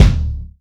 • Wet Steel Kick Drum Sound D Key 107.wav
Royality free kick drum sample tuned to the D note. Loudest frequency: 329Hz
wet-steel-kick-drum-sound-d-key-107-iti.wav